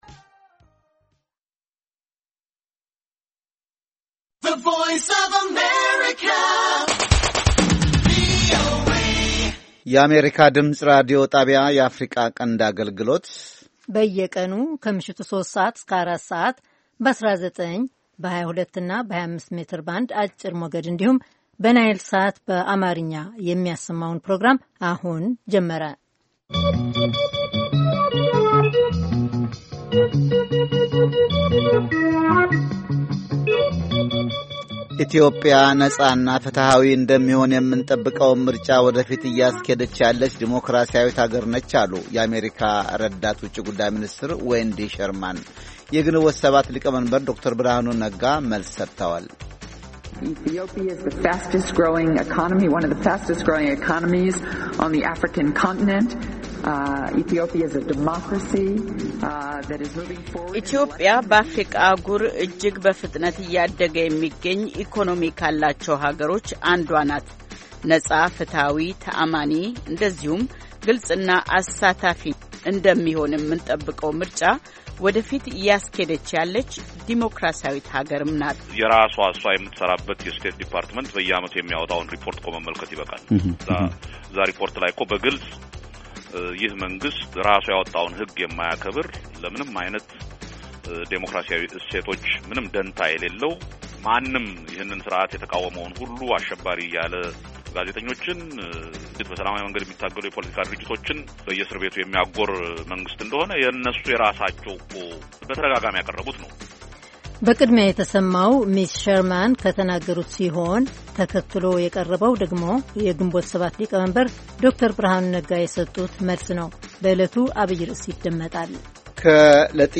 ከምሽቱ ሦስት ሰዓት የአማርኛ ዜና 04-17-15